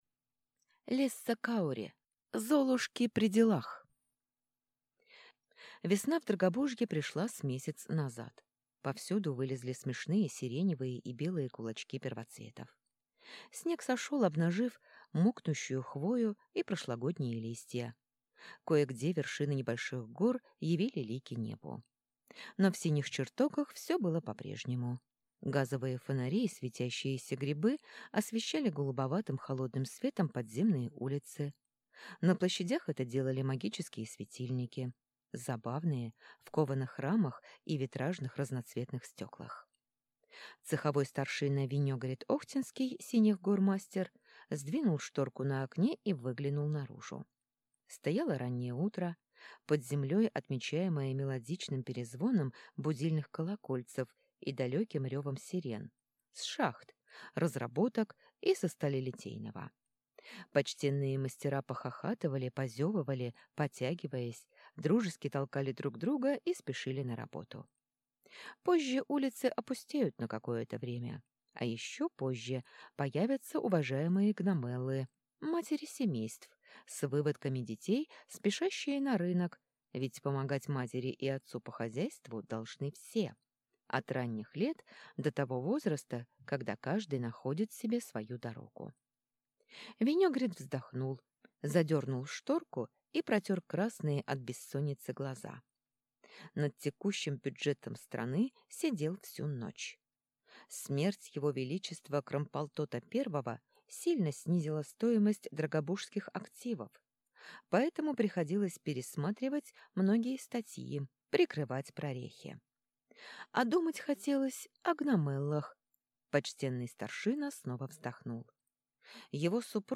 Аудиокнига Золушки при делах | Библиотека аудиокниг